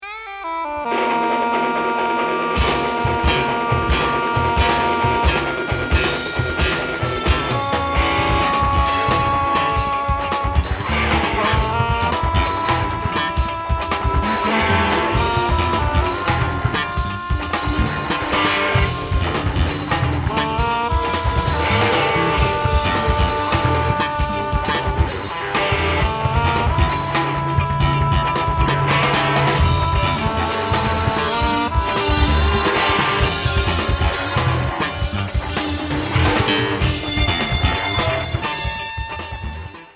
ai sassofoni
alla batteria
alle chitarre